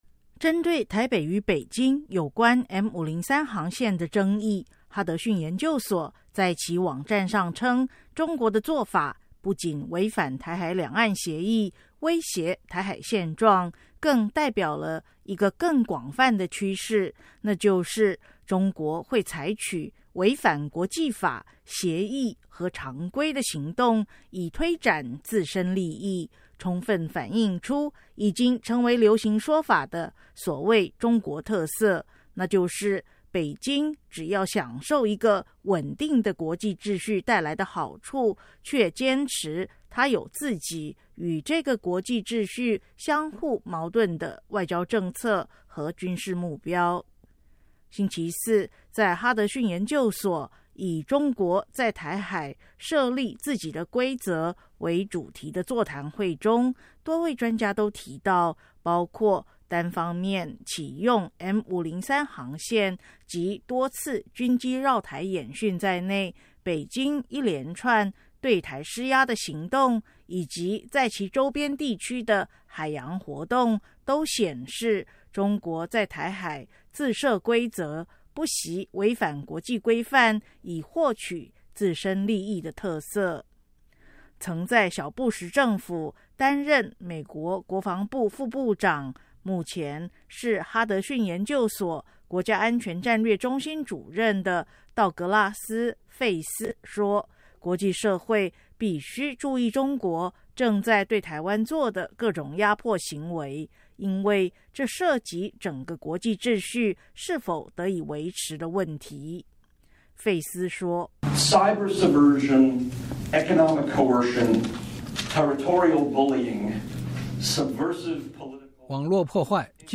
哈德逊研究所专家谈“中国在台海自设规则”
星期四，在哈德逊研究所以“中国在台海设立自己的规则”为主题的座谈会中，多位专家都提到，包括单方面启用M503航线及多次军机绕台演训在内，北京一连串对台施压的行动以及在其周边地区的海洋活动，都显示中国在台海“自设规则”、不惜违反国际规范以获取自身利益的特色。